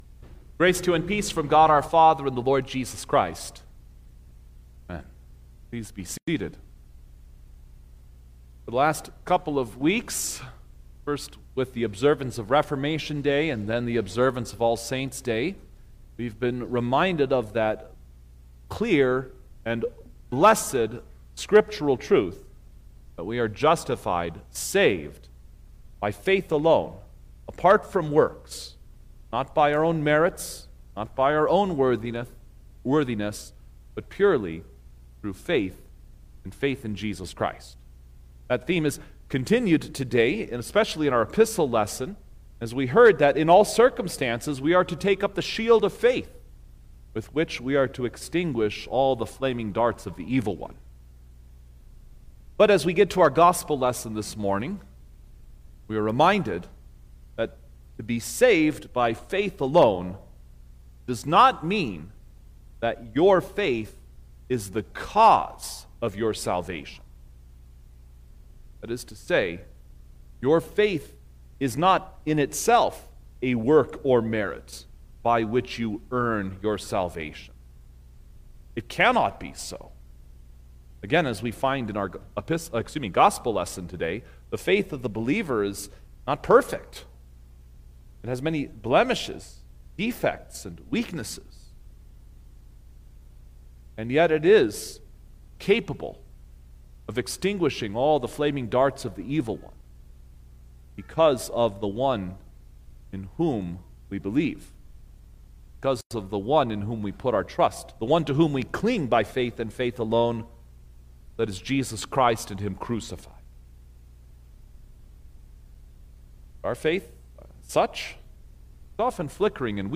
November-9_2025_Twenty-First-Sunday-after-Trinity_Sermon-Recording-Stereo.mp3